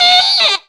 OVERBLOW.wav